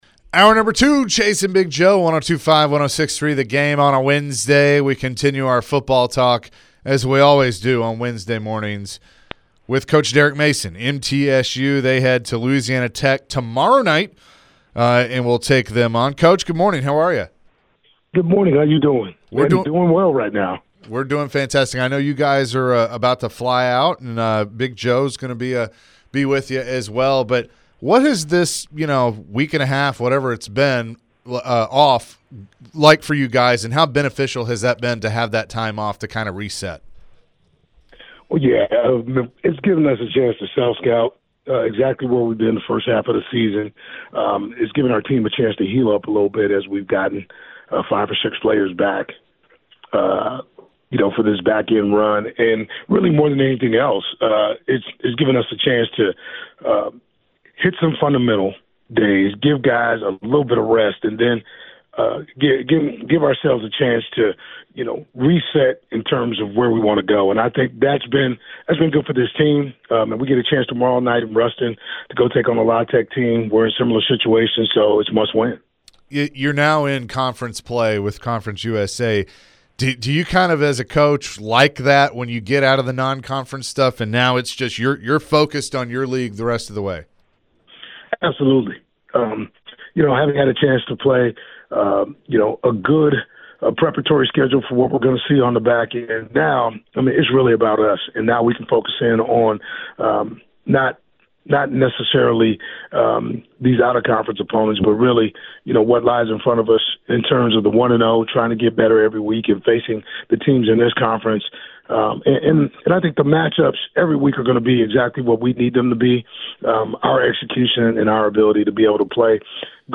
MTSU Head Football Coach Derek Mason joined the show ahead of tomorrow night's game against LA Tech. He then talked about how the schedule in the CUSA is different than coaching in the SEC and playing during the week instead of on the weekends.